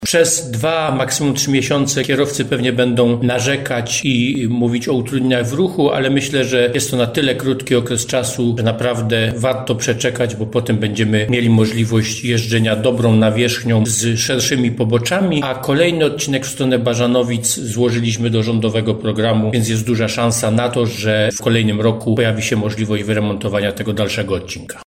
Starosta cieszyński podkreśla, że inwestycja rusza po ponad dwóch latach poślizgu.